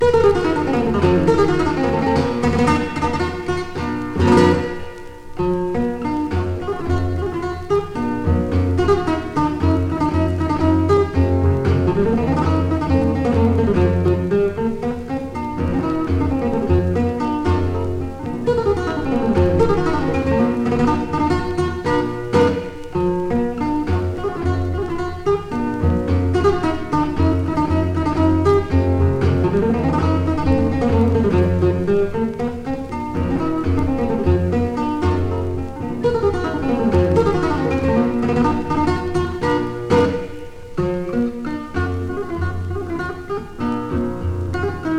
フラメンコスタイルと言えば彼と思える演奏曲の数々、抑揚の効いた超絶技巧で繰り出される音は強烈の一言。
World, Flamenco　USA　12inchレコード　33rpm　Stereo